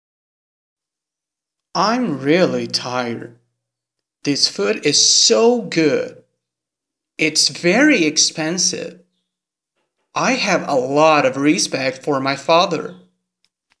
Confira a comparação dos exemplos acima sem os advérbios e com os advérbios. Repare como é nítida a diferença do produto final ao verbalizarmos as frases.